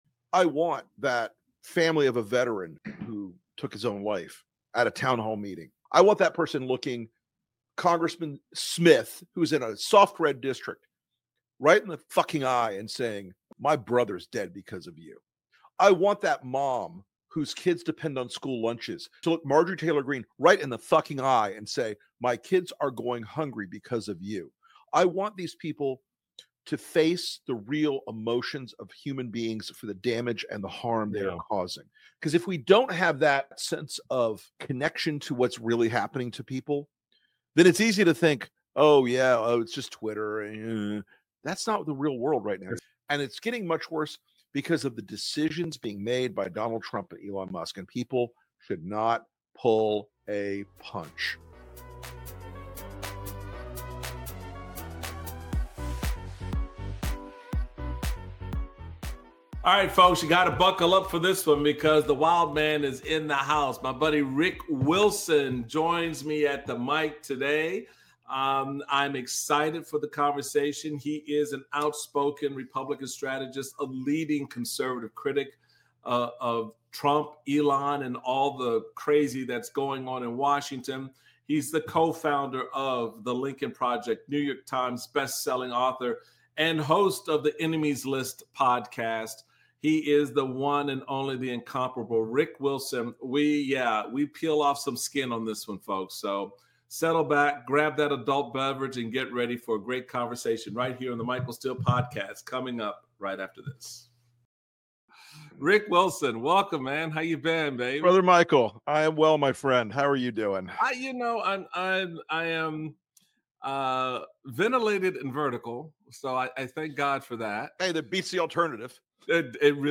Michael Steele speaks with Republican strategist, Lincoln Project co-founder and NYT best-selling author, Rick Wilson. The pair discuss the havoc Elon Musk and Donald Trump have wreaked on national and global stability.